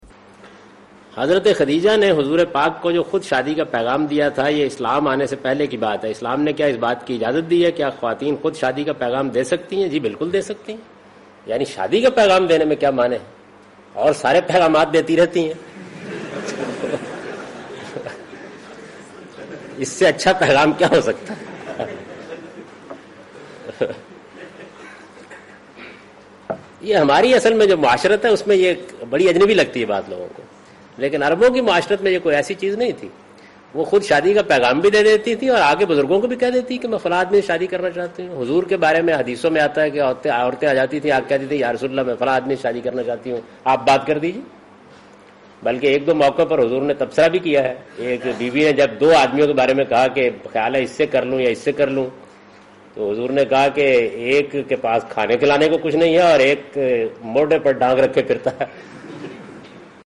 Javed Ahmad Ghamidi responds to the question ' Does Islam allow women to propose someone for marriage'?